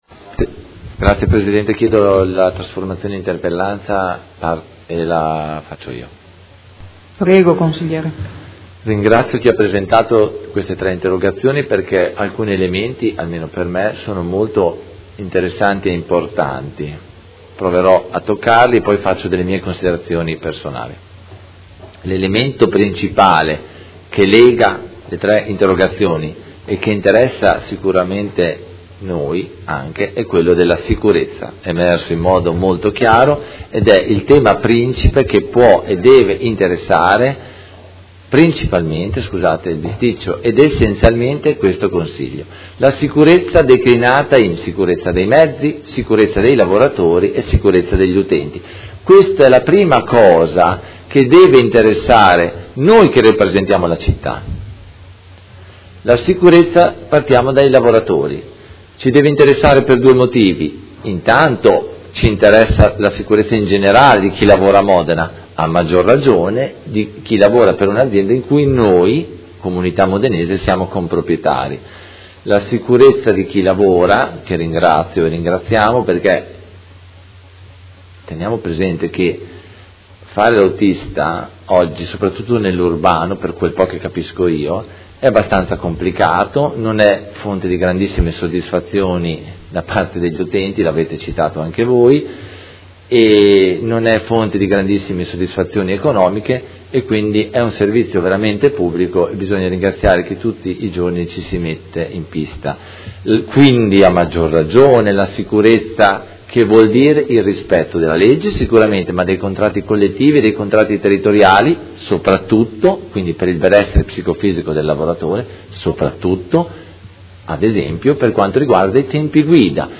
Antonio Carpentieri — Sito Audio Consiglio Comunale